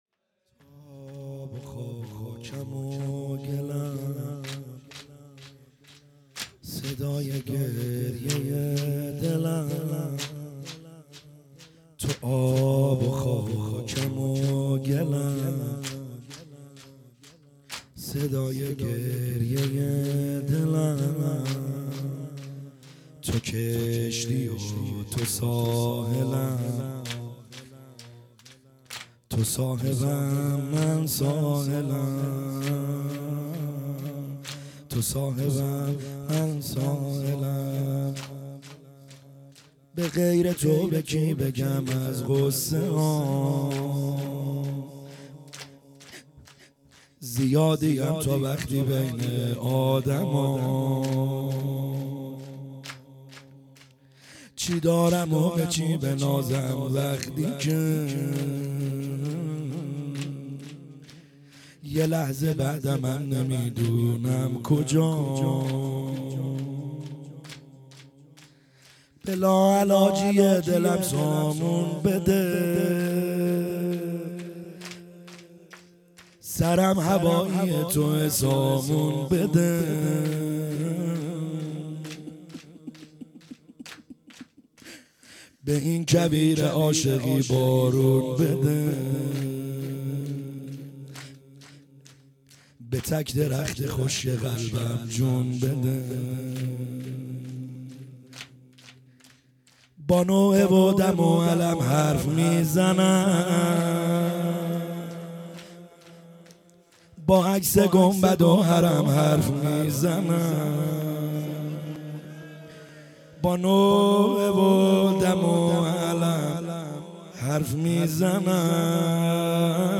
خیمه گاه - بیرق معظم محبین حضرت صاحب الزمان(عج) - شور | تو ابو گلمو خاکم